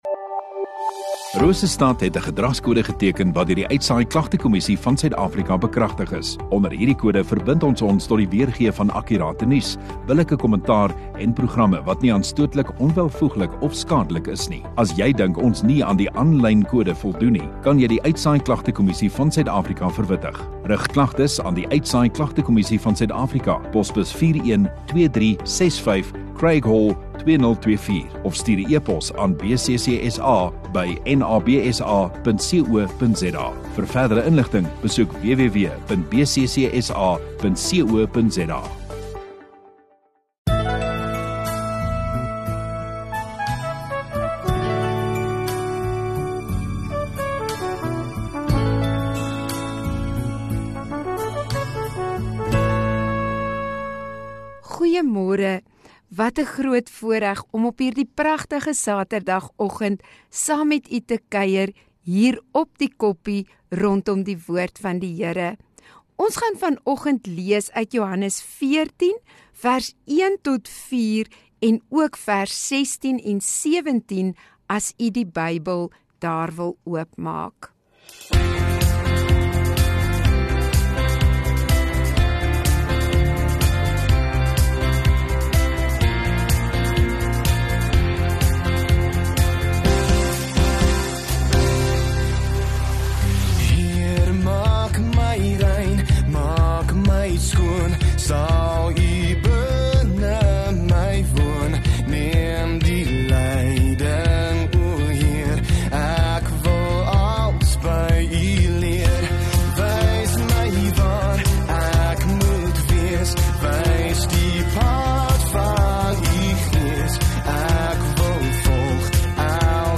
11 May Saterdag Oggenddiens